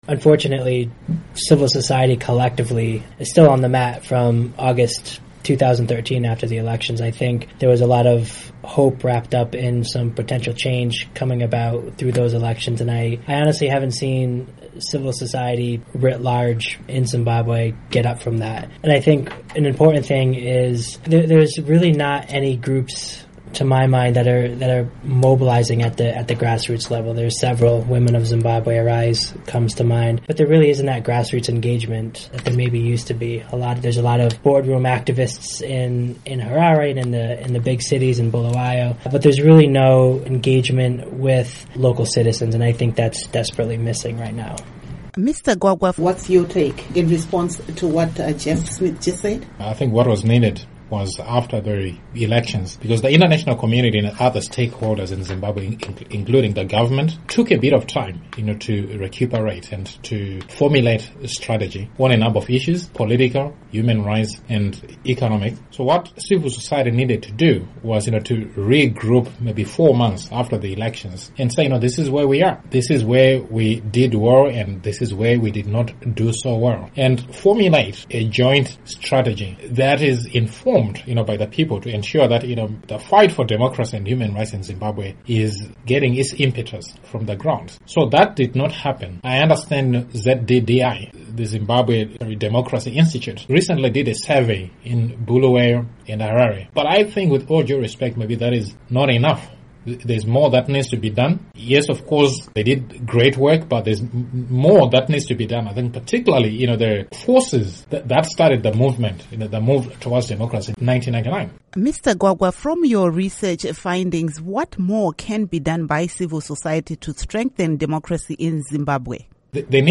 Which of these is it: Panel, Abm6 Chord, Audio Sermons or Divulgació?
Panel